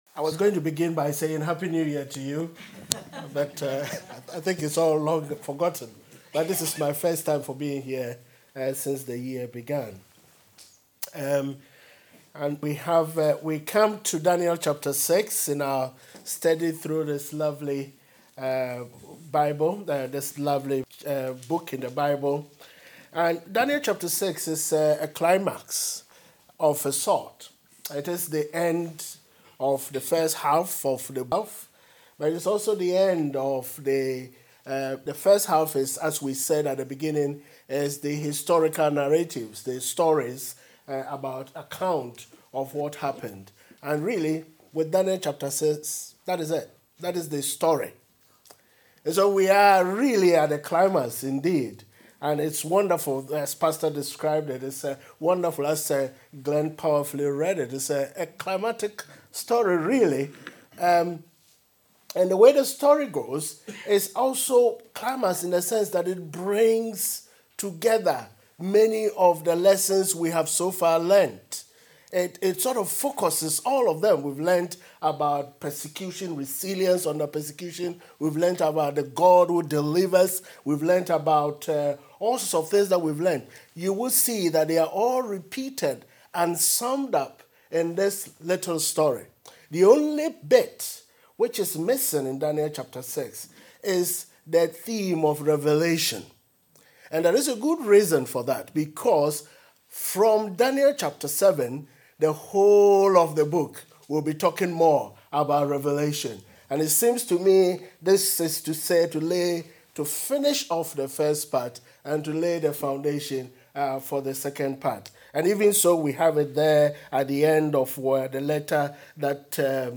This entry was posted in Messages and tagged Daniel, Lion's den experience, the habit of faith.